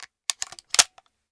Reloading_begin0005.ogg